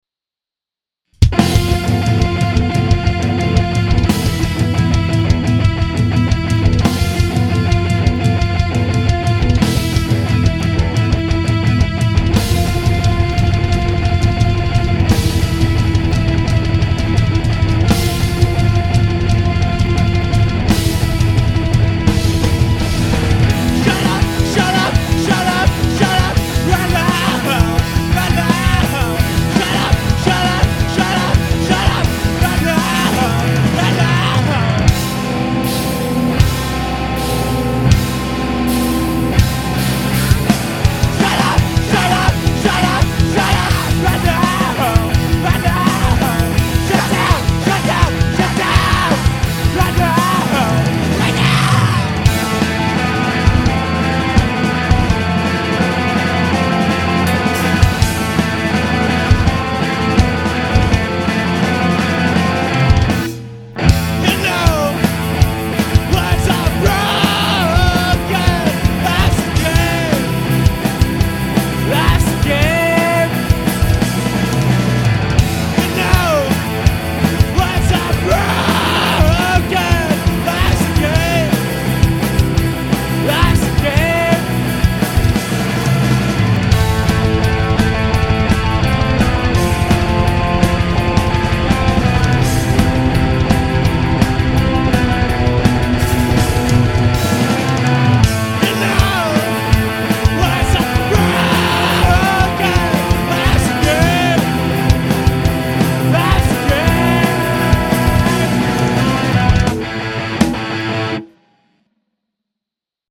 Vocals & Guitar
Drums
Bass & Vocals Recorded by
at 57 Heaven